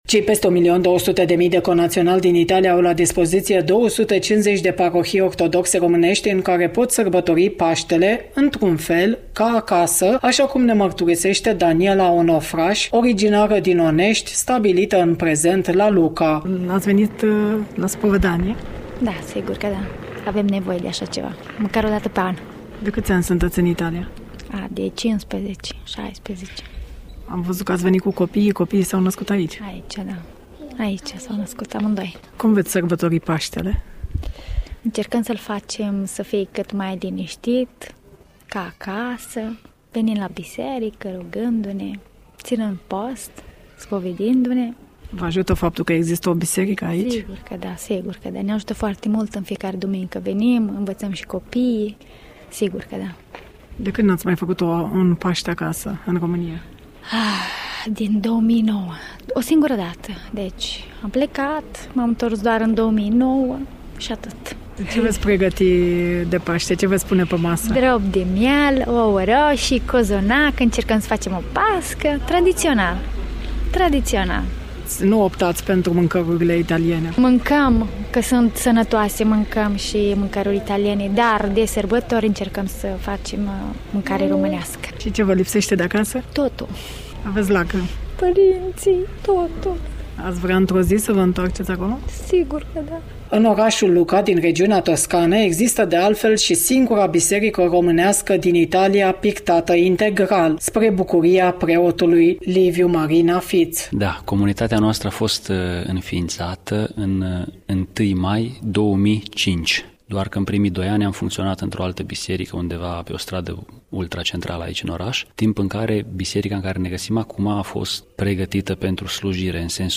Dialog la biserica ortodoxă din oraşul Lucca, regiunea Toscana. Emoţii şi gânduri ale celor care sunt stabiliţi în peninsulă.